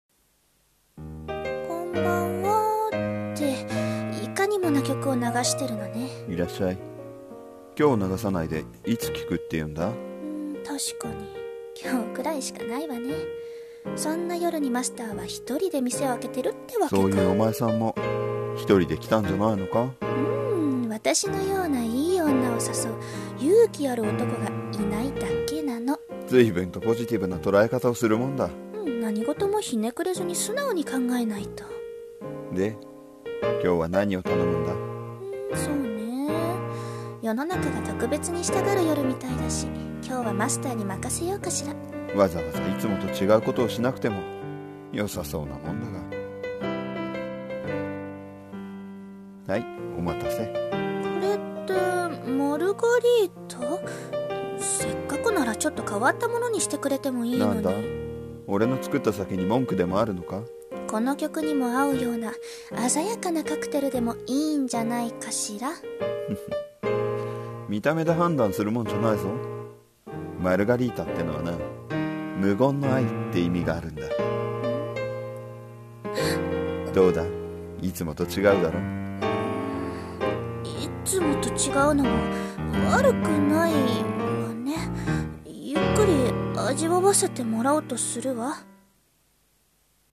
【二人声劇】Unusual night